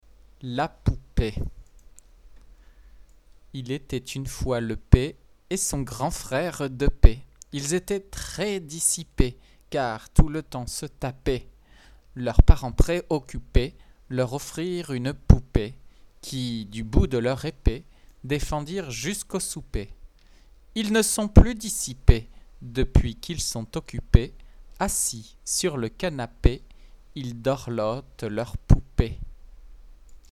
Pour la prononciation du bon nombre de pieds à titre indicatif.